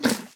minecraft / sounds / mob / llama / eat2.ogg
eat2.ogg